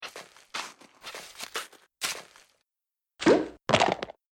teleport_disappear.ogg